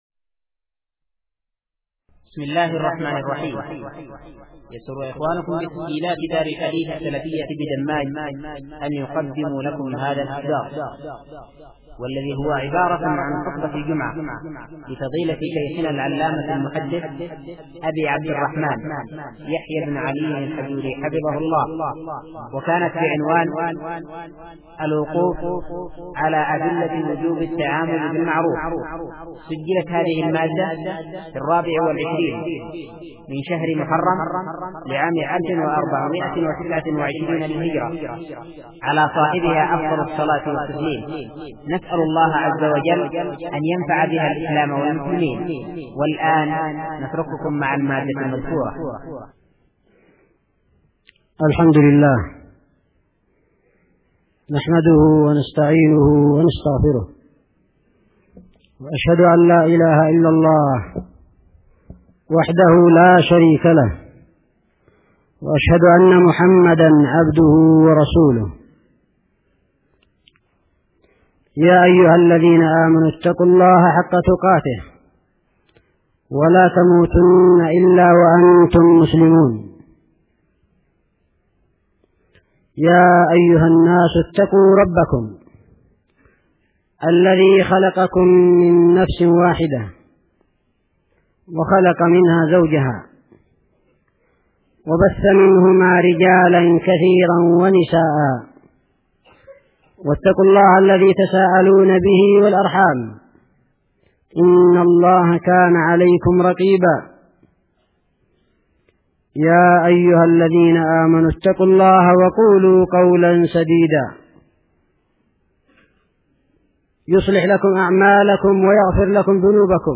خطبة جمعة بتاريخ: (23/محرم/ 1429هـ)